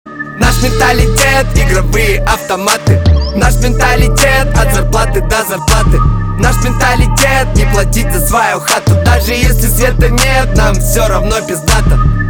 • Качество: 320, Stereo
русский рэп
качающие
Bass
матерные